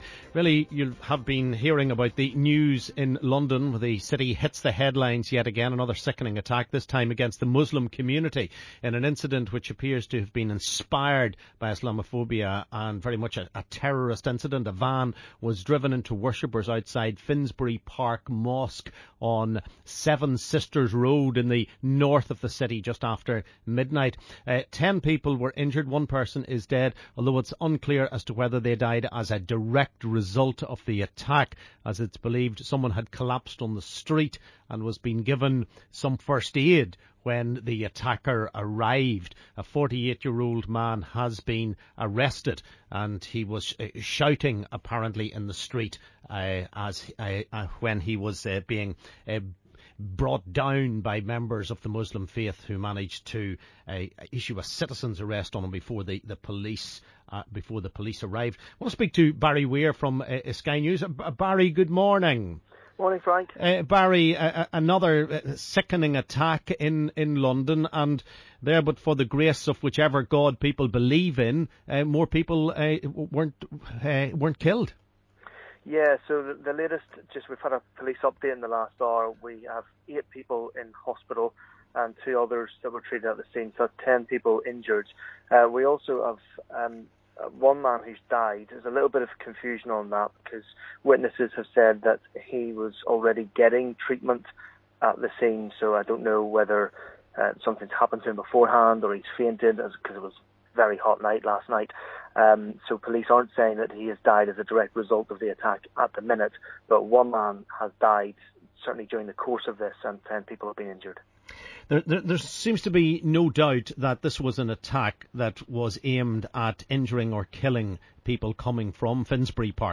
reports from London after latest terror attack